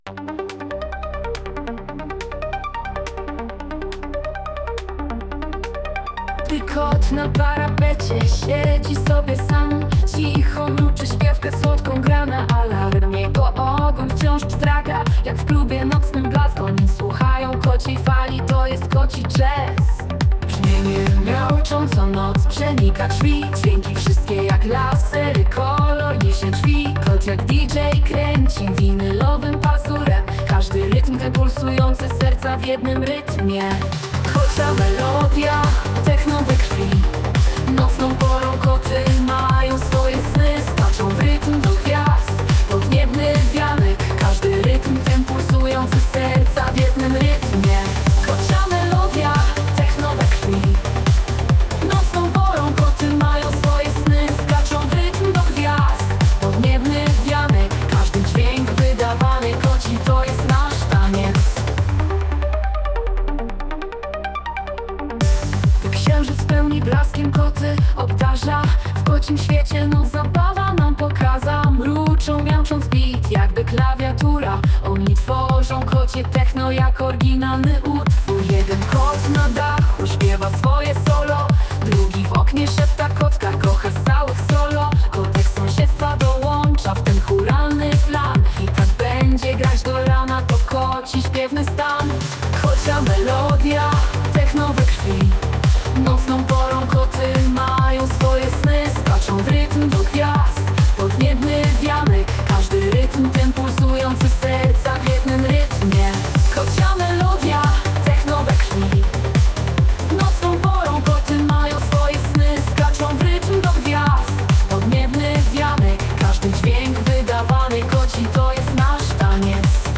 Model: Suno 3.5